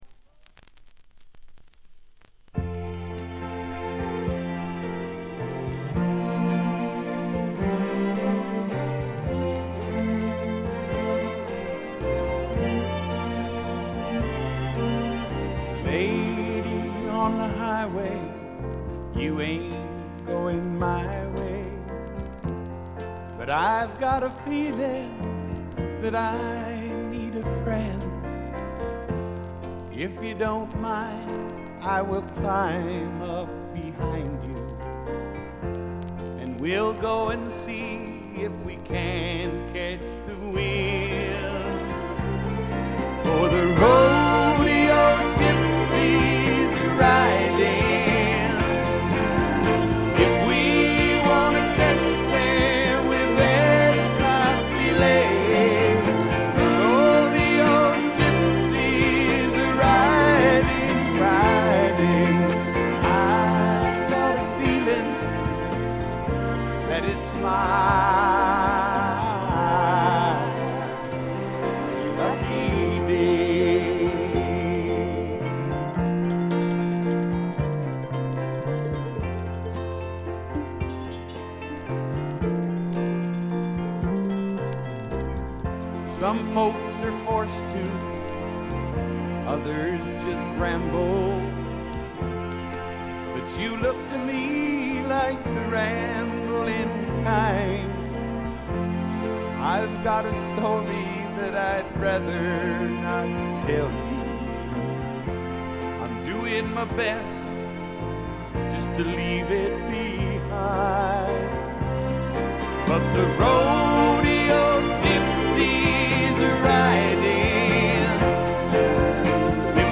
バックグラウンドノイズ、チリプチ多め（特にB1あたり）。散発的なプツ音少し。
スワンプ系シンガーソングライター作品の基本定番。
試聴曲は現品からの取り込み音源です。
Vocals, Acoustic Guitar